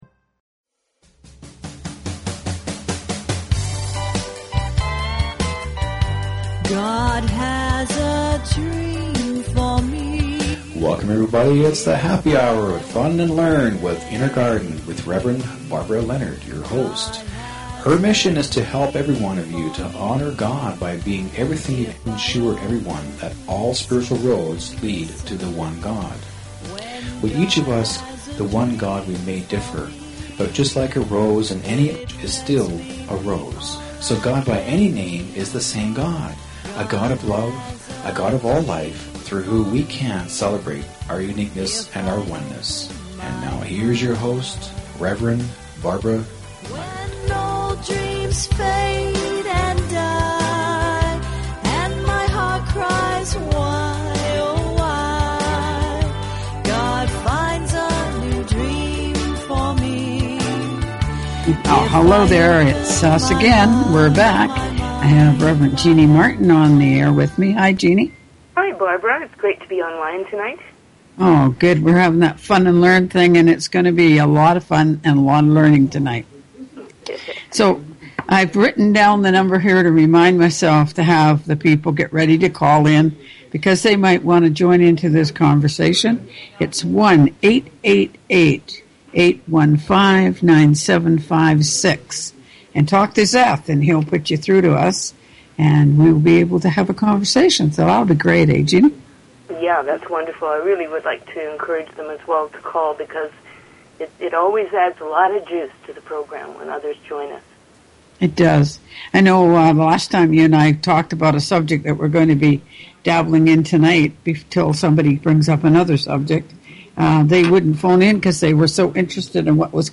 Talk Show Episode, Audio Podcast, Fun_and_Learn_with_Inner_Garden and Courtesy of BBS Radio on , show guests , about , categorized as